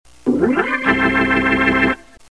[影视音效][高雅震撼的管风琴][剪辑素材][免费音效下载]-8M资料网